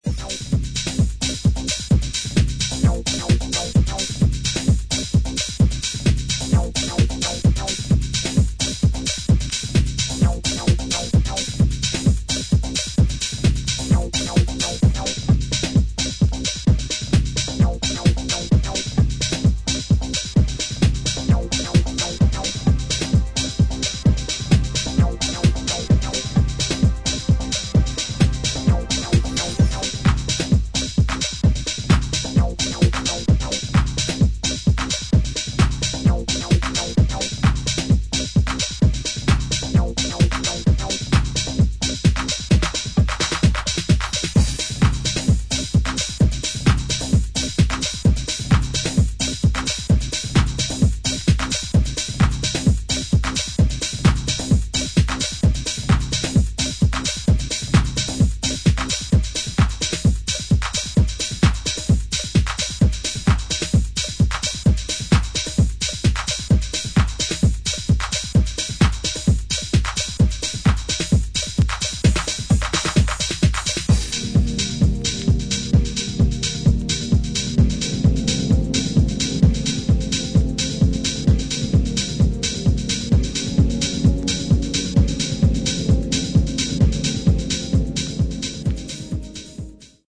シンプルな編成ながら深みが感じられるエクスペリメンタル的要素が垣間見える90"テクノ傑作